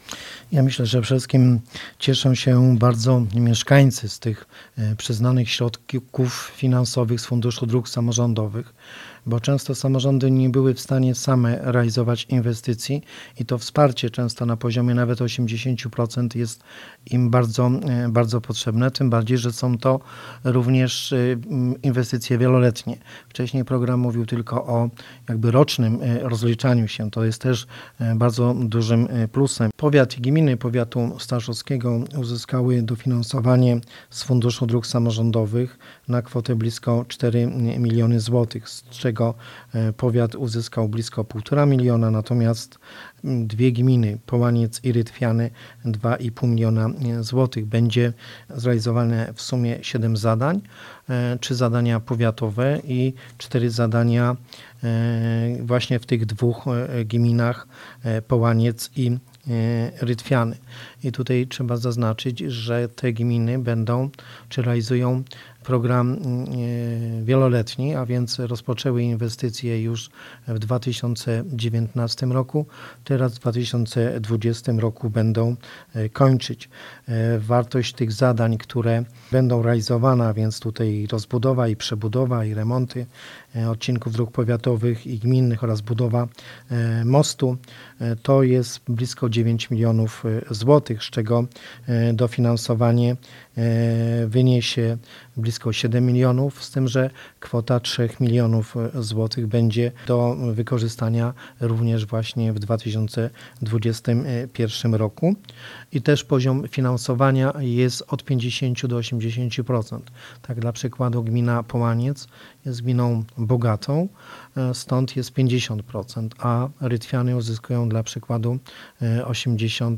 Pozwoli to na realizację inwestycji o wartości około 9 milionów złotych. O szczegółach mówi poseł Marek Kwitek: